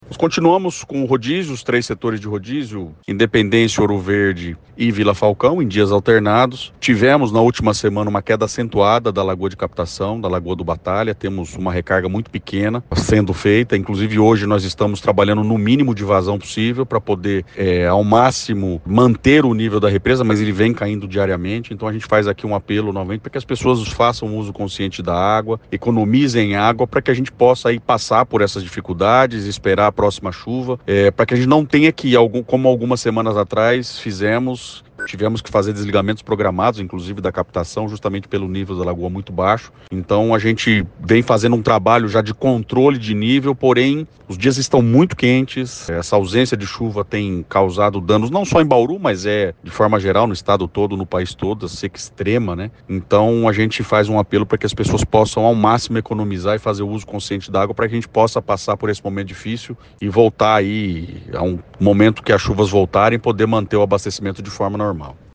Purini destaca que o nível da lagoa de captação teve uma queda acentuada e o rodízio continua: